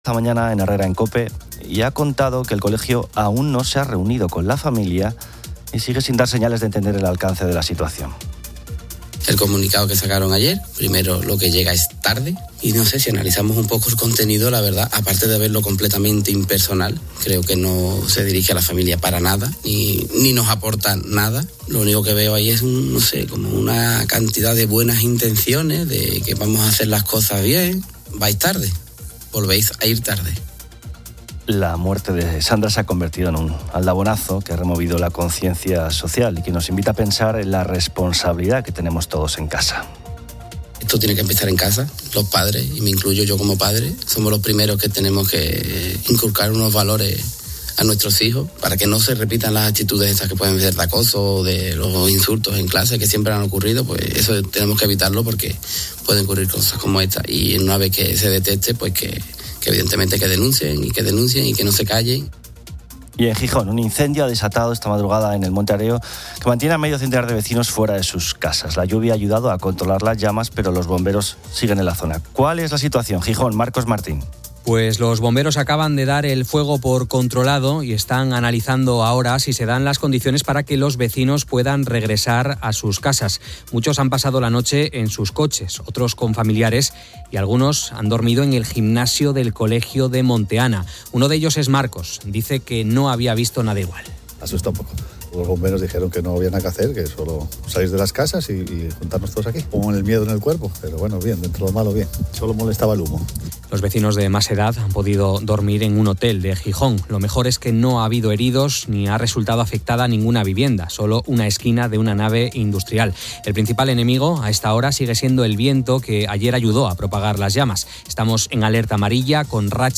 COPE informa sobre un incendio en Gijón y alertas por viento en el norte. La UE aprueba nuevas sanciones a Rusia, mientras el Real Madrid y el Athletic Club ganan en la Champions. COPE debate sobre trabajos peligrosos: un oyente transporta ácidos, otro escala fachadas, uno es pirotécnico.